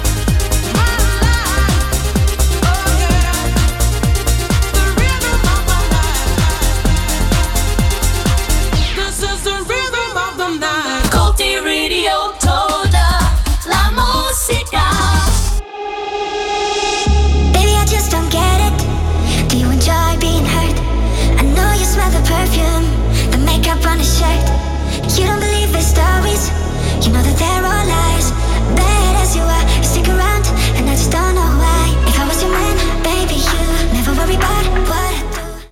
Tema musical i identificació